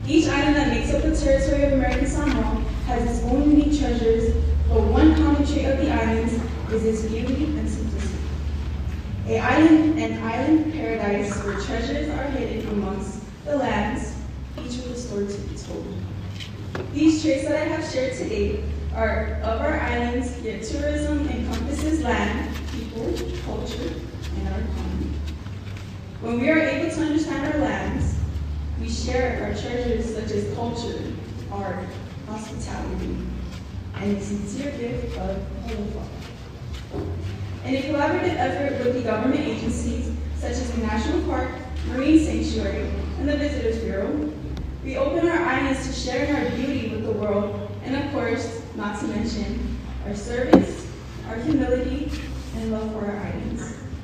They started with prepared presentations on their selected topics, and then they answered judges questions.
Here are excerpts from the four contestants on the topics of education, drugs, environment and tourism.